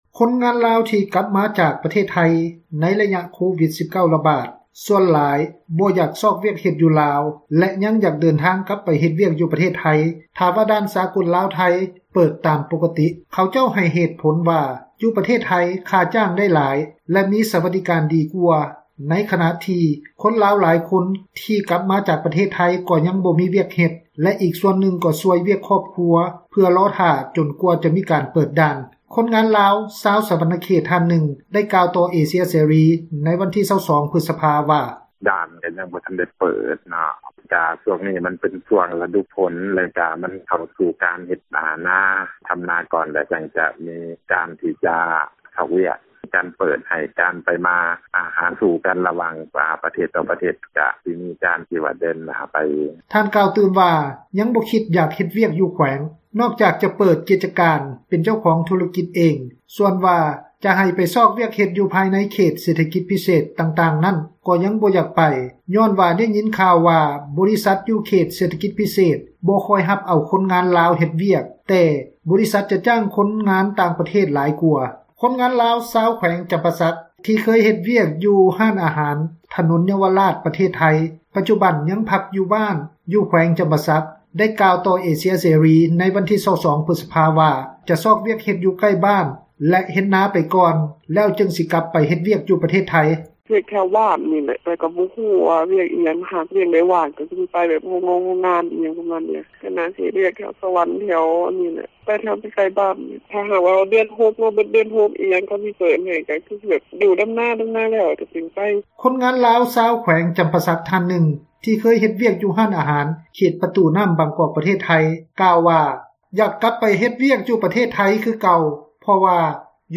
ຄົນງານລາວຢູ່ແຂວງຈຳປາສັກ ອີກທ່ານນຶ່ງ ທີ່ເຄີຍເຮັດວຽກ ຢູ່ຮ້ານອາຫານ ເຂດປະຕູນ້ຳ ບາງກອກ ປະເທດໄທ ໄດ້ກ່າວວ່າ ຢາກກັບໄປ ເຮັດວຽກ ຢູ່ໄທຄືເກົ່າ ເພາະຢູ່ບ້ານບໍ່ມີຫຍັງເຮັດ:
ສ່ວນວ່າ ໂຮງງານຢູ່ແຂວງສວັນນະເຂດ ກໍຍັງບໍ່ທັນເປີດຮັບຄົນງານໃໝ່ ຍ້ອນວ່າ ຍັງບໍ່ທັນມີຕຳແໜ່ງວ່າງ, ຜູ້ປະກອບການໂຮງງານແຫ່ງ ນຶ່ງຢູ່ເຂດເສຖກິດພິເສດ ໄດ້ກ່າວວ່າ: